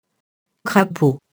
crapaud [krapo]